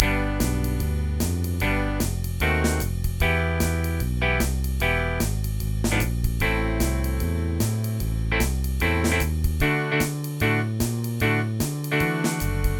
in the swing jazz style at 150 bpm